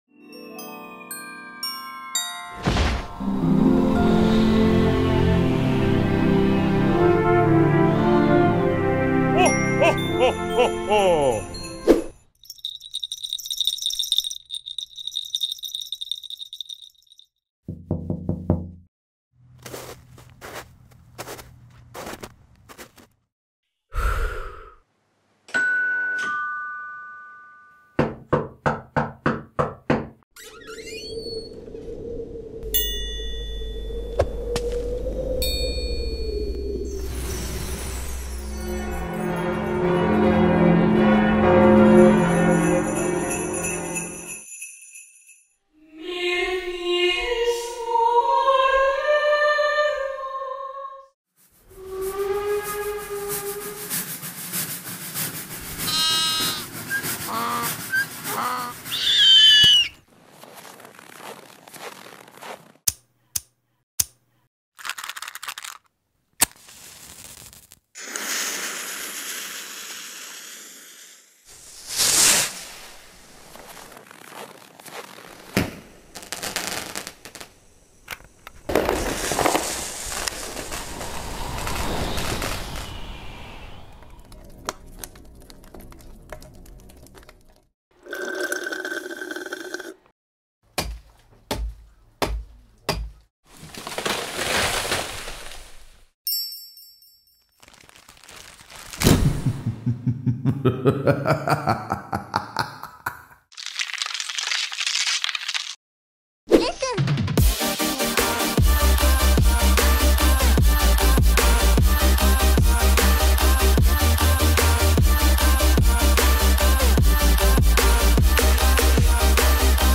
Christmas Sound Effects I Free Download.mp3